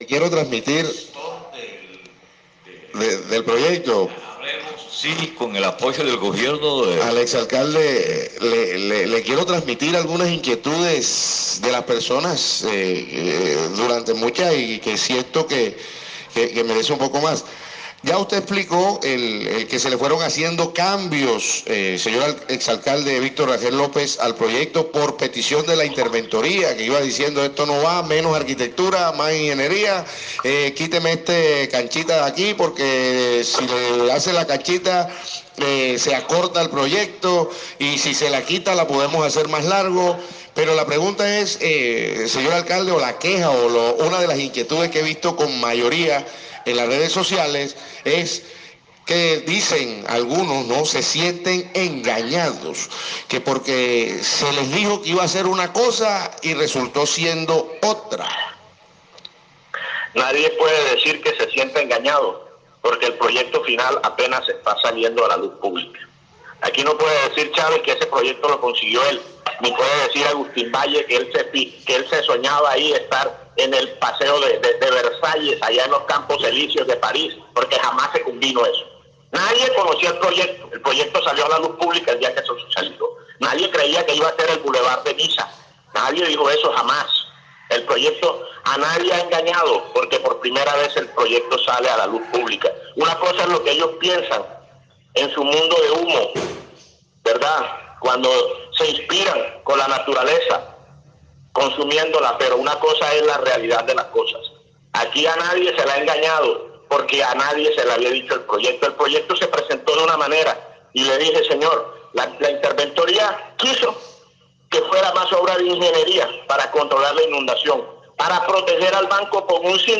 La bola de nieve que se le creció al exalcalde se hizo mucho más grande, cuando este reaccionó a través de una emisora local del municipio, en donde tildó a sus críticos de imaginarse cosas que él no ha dicho e incluso los agredió verbalmente pretendiendo señalarlos de ‘consumidores de la naturaleza’ (escuchar audio abajo):
Intervención de Víctor Rangel sobre la alameda de El Banco